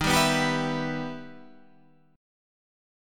Em/D# Chord